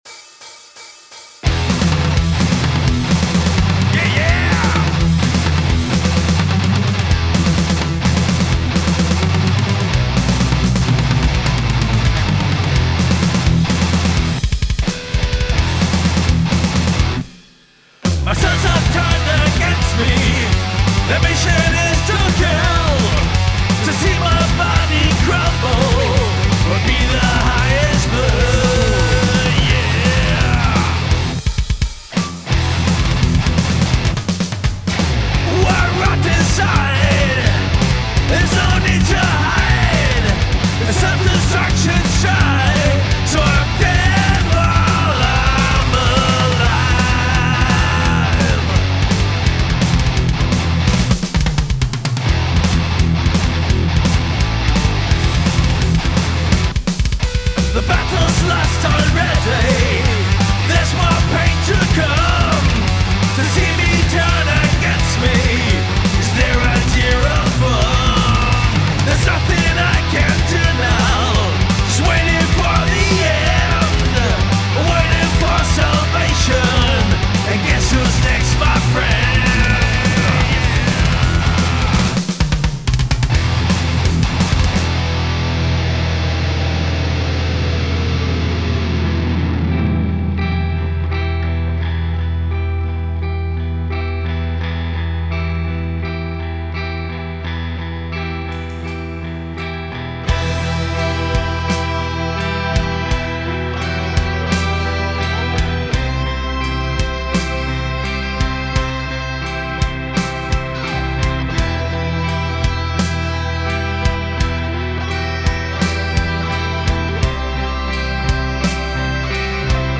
Ich habe nochmal etwas gefummelt, u.a. bisschen Feintuning, bisschen Parallel-Gedöns, dem Mittelteil ein Mellotron verpasst und die Transition etwas verziert.